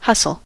hustle: Wikimedia Commons US English Pronunciations
En-us-hustle.WAV